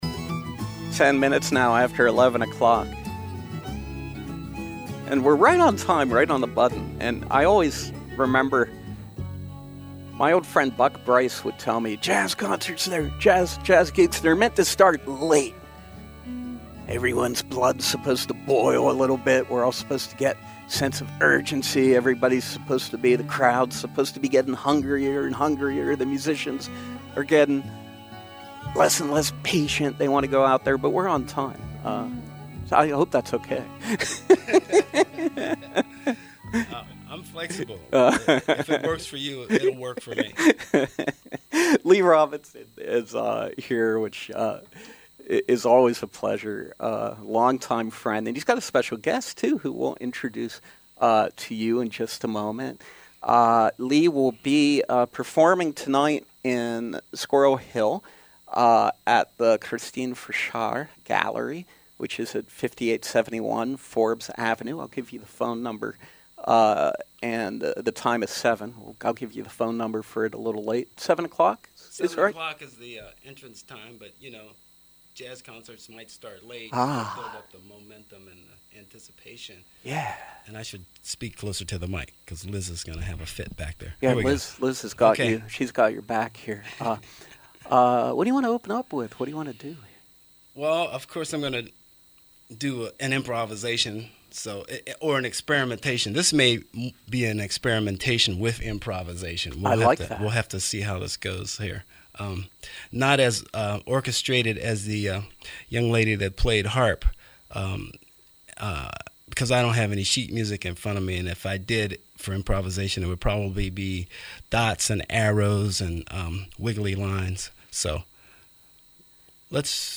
live in our studios.
jazz musician